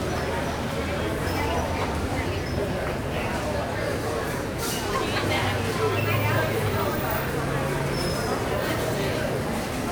shopping.ogg